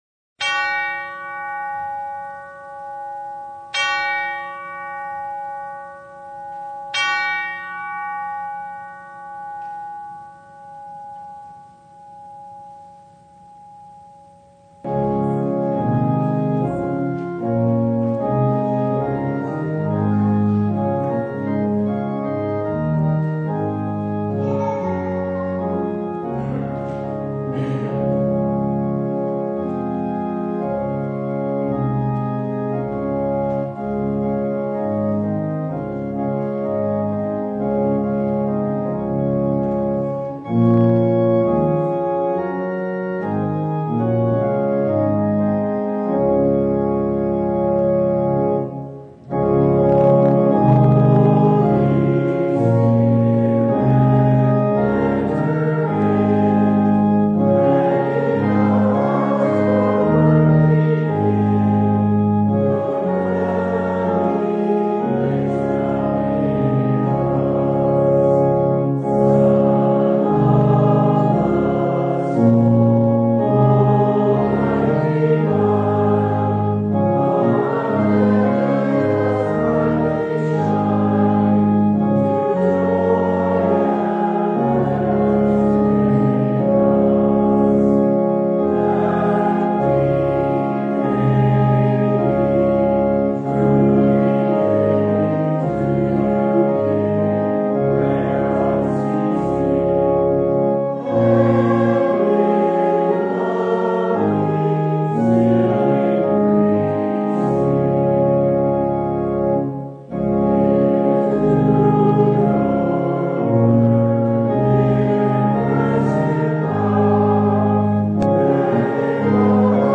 Service Type: The Feast of Pentecost